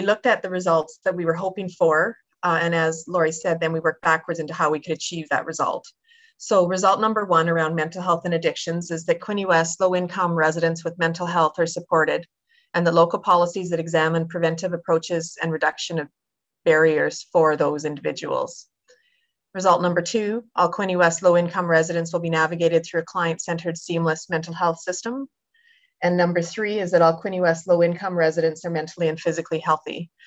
At Monday’s Quinte West council meeting